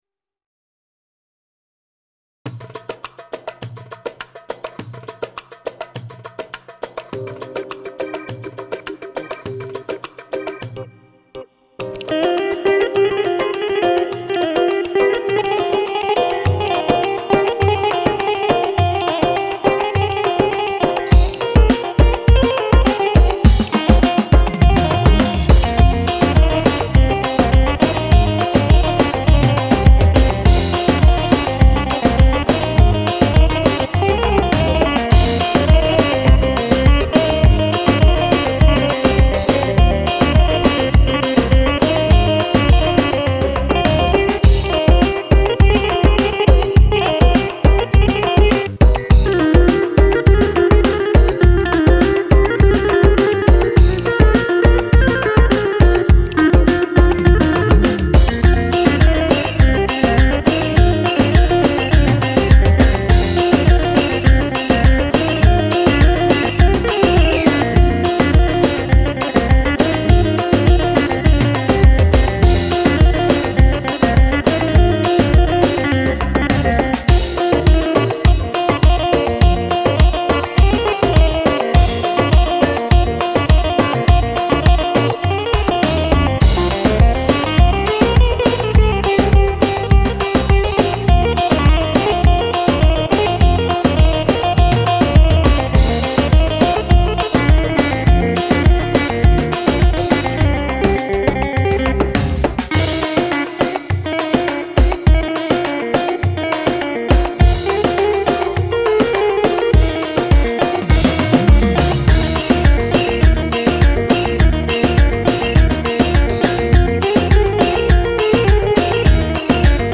korg pa2x (deneme) hey onbesli-halkali seker
dinlemenizi tavsiye ederim bence guzel oldu kendi duzenledigim ritm ile yaptim begenen olursa alt yapi yapip gonderebilirim saygilar:))